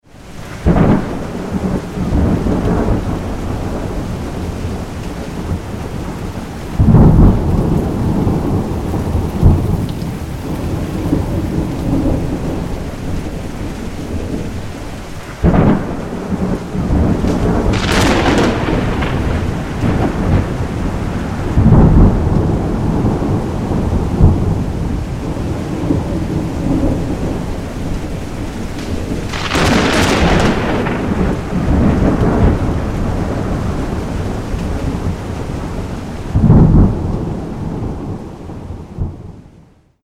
iPhone / iPad / iPod Vorschau Ein Gewitter (Thunderstorm sound)
syncsouls_4JZ_snippet_sound_sommergewitter.mp3